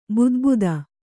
♪ budbuda